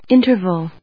音節in・ter・val 発音記号・読み方
/ínṭɚv(ə)l(米国英語), ˈɪntɜ:vʌl(英国英語)/